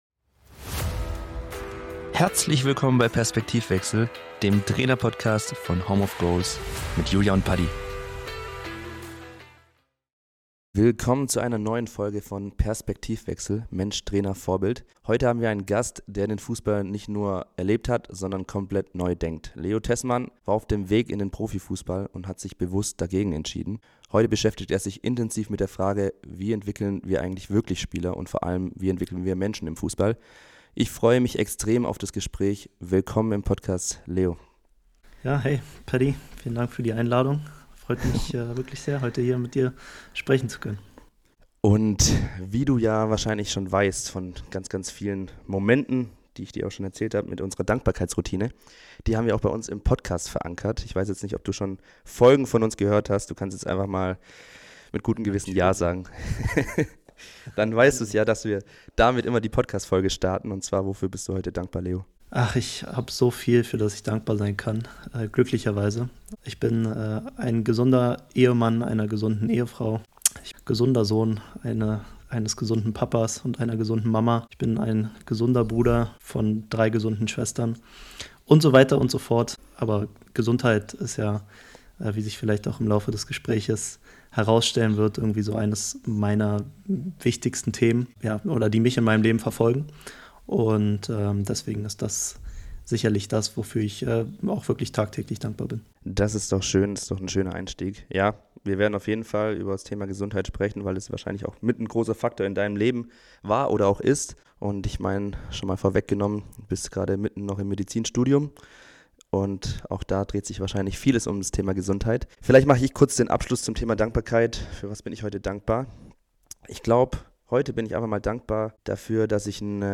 #19 Im Gespräch mit